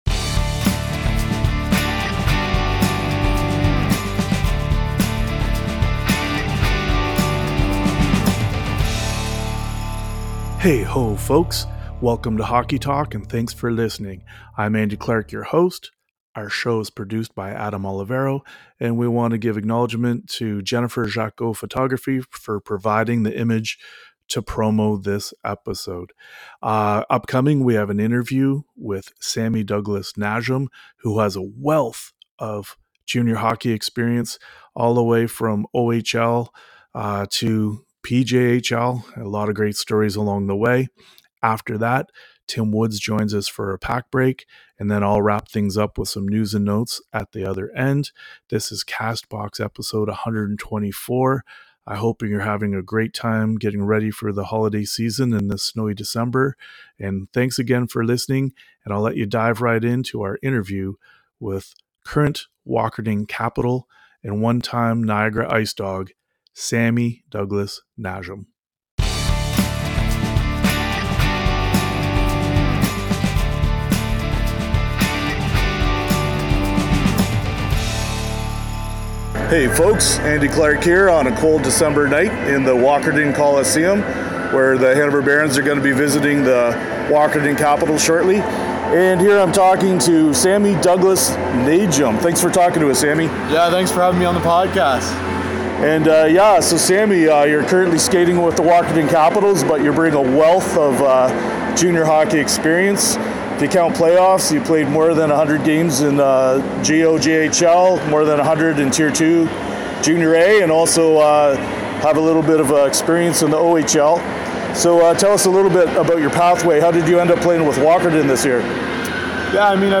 The show is broadcast live on Wightman TV Channel 6 and here in Podcast form.
Each week they feature a new guest either in the studio or by phone.